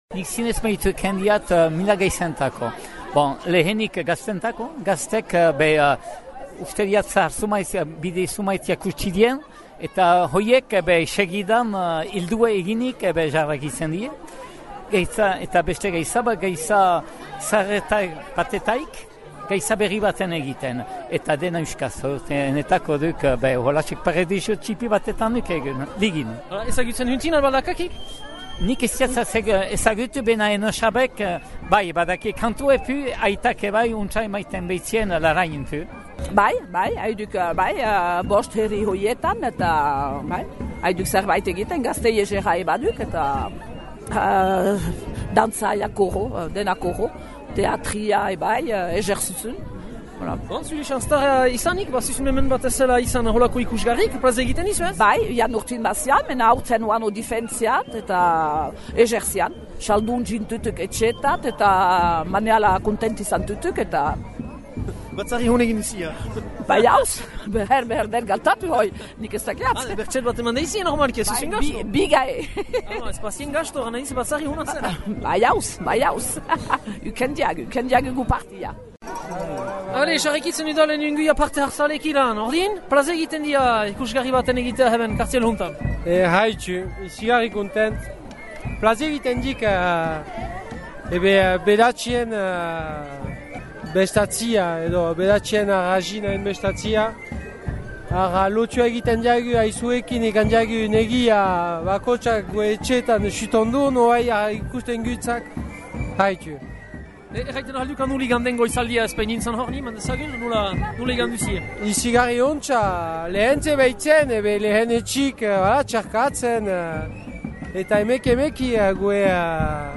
Erreportajea:
erreportjealbadakak.mp3